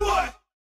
TRAP HOUSE VOX (2) 22.wav